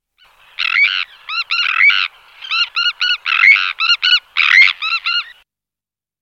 Sterne caugek
Sterna sandvicensis
caugek.mp3